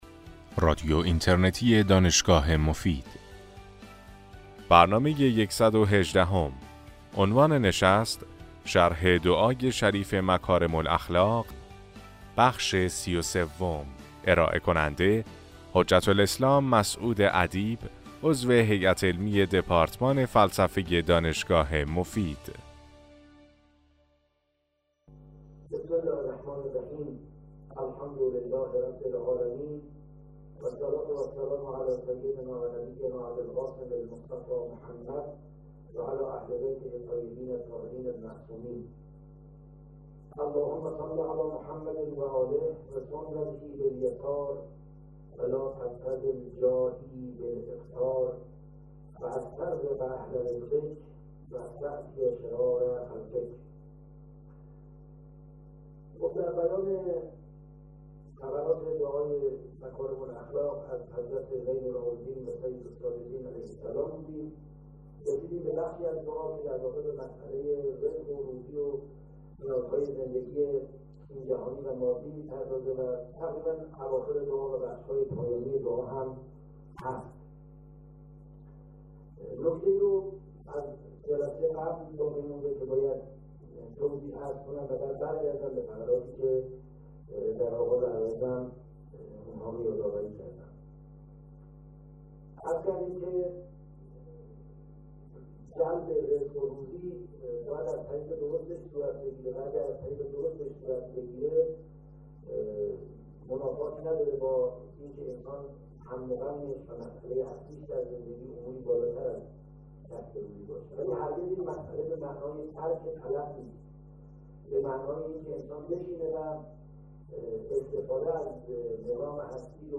سلسله سخنرانی